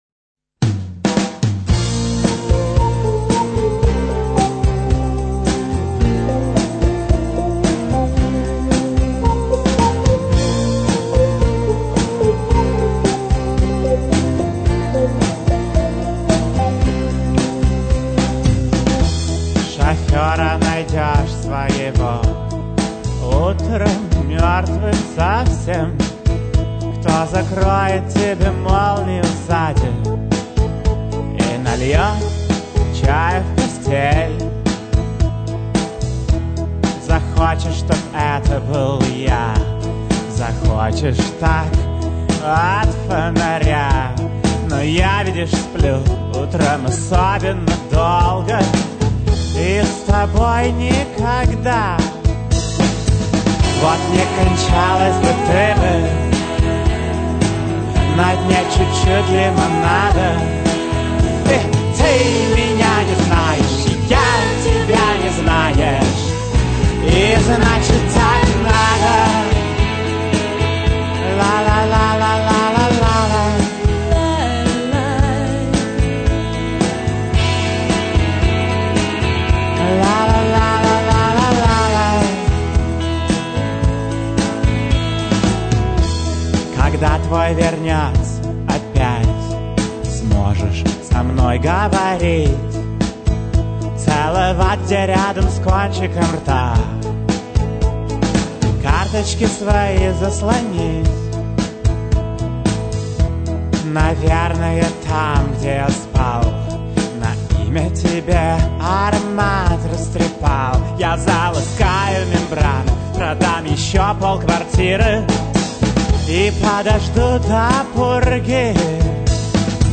- клавишные
- guitars
- bass
- drums
- percussions
- saxophone
- piano
- backing vocal
- голос